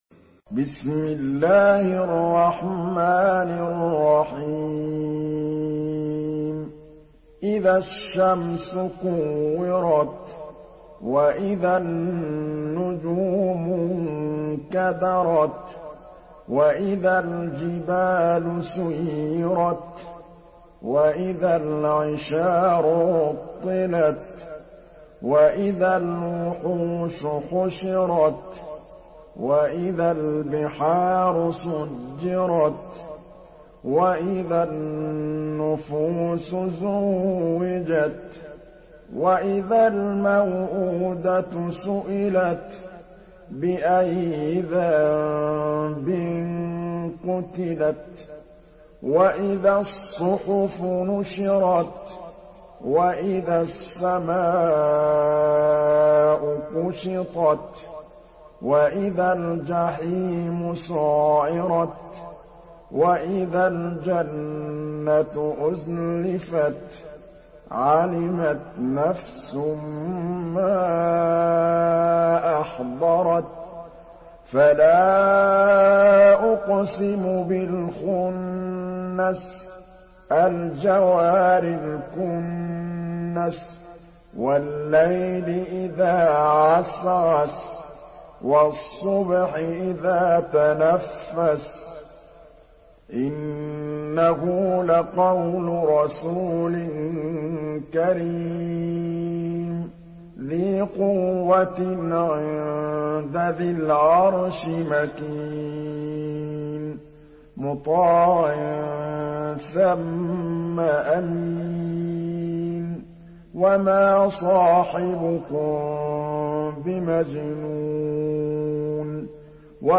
Surah Tekvir MP3 in the Voice of Muhammad Mahmood Al Tablawi in Hafs Narration
Murattal Hafs An Asim